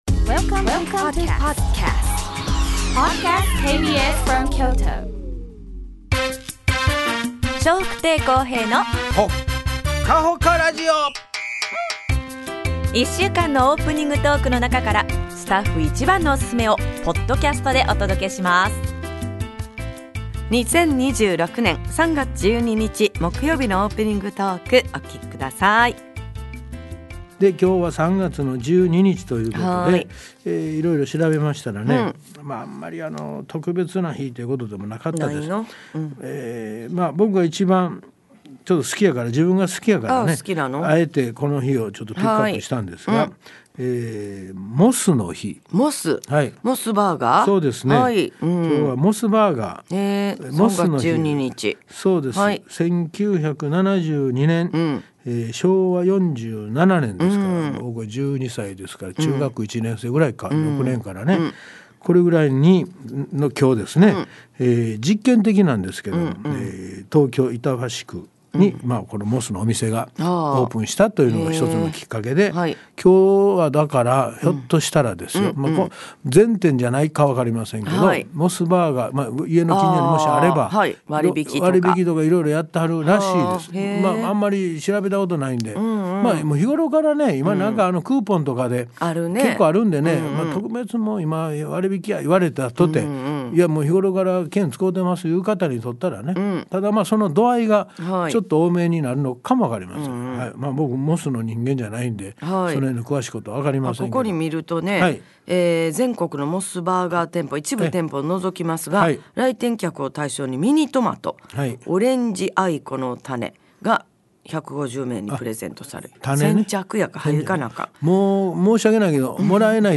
2026年3月12日のオープニングトーク